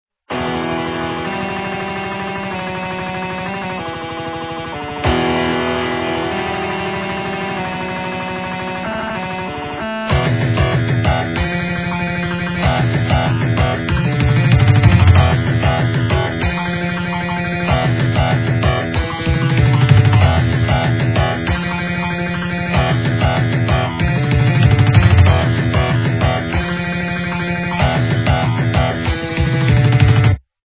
- рок, металл